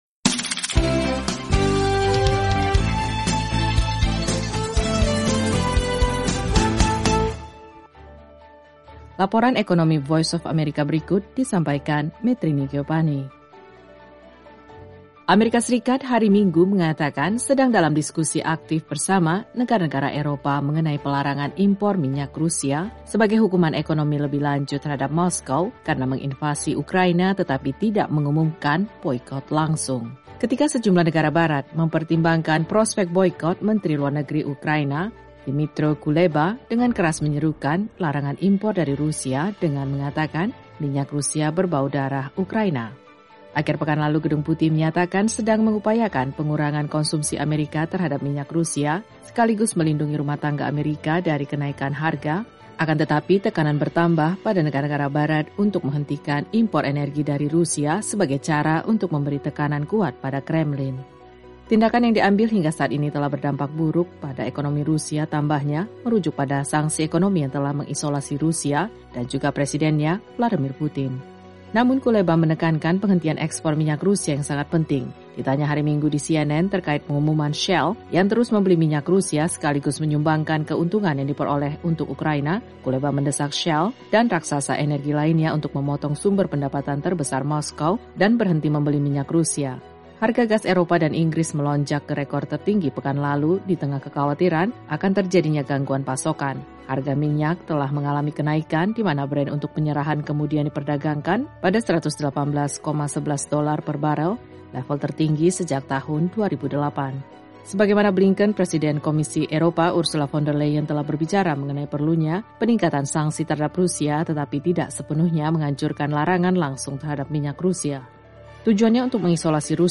Laporan Ekonomi VOA kali ini mengenai AS dan Eropa yang mempertimbangkan larangan impor minyak Rusia sebagaimana dikemukakan Menlu AS Antony Blinken. Simak juga laporan tentang ritel Rusia yang membatasi penjualan makanan untuk melawan pasar gelap.